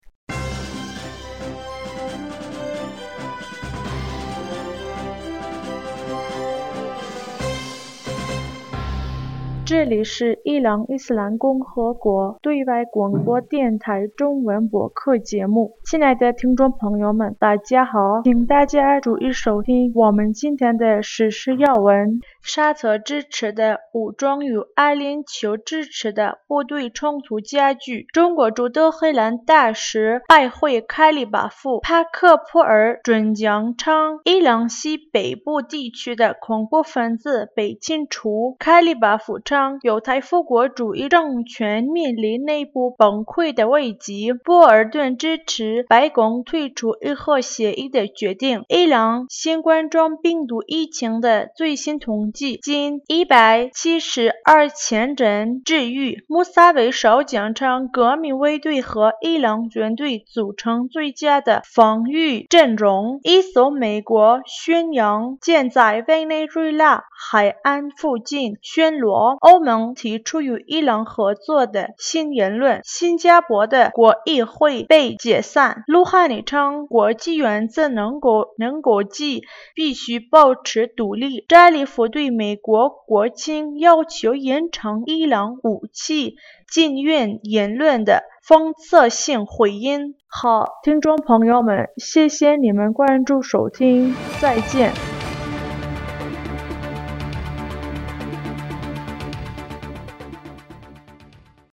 2020年6月24日 新闻